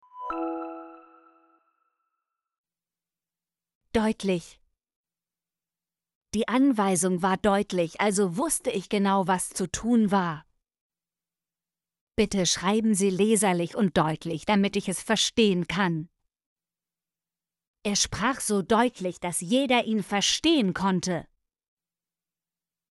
deutlich - Example Sentences & Pronunciation, German Frequency List